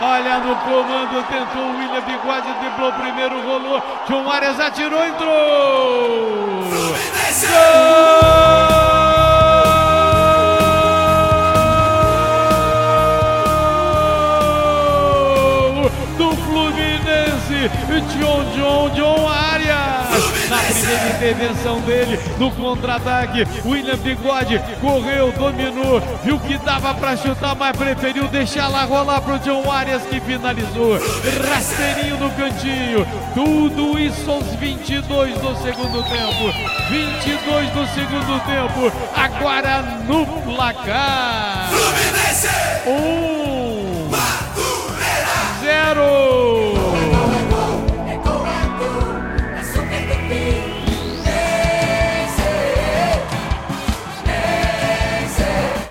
Ouça o gol da vitória do Fluminense sobre o Madureira pelo Carioca com a narração do Garotinho